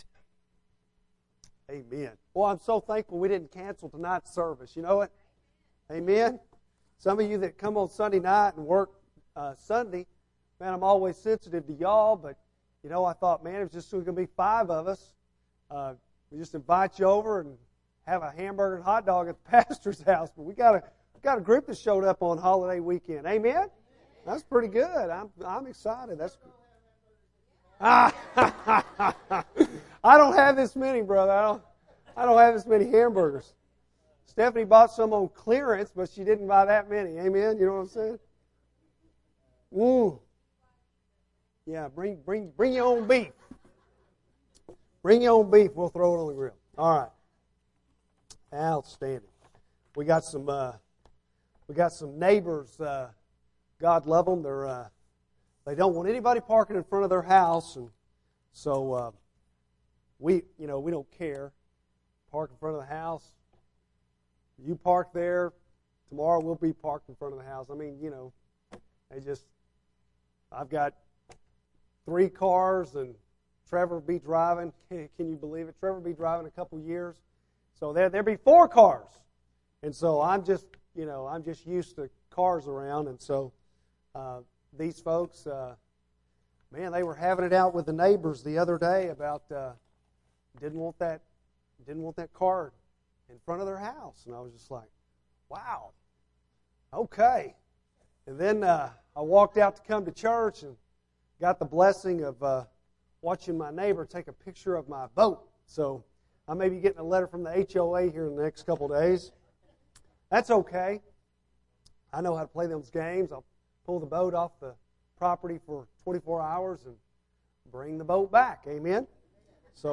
Bible Text: I Corinthians 12:8-11 | Preacher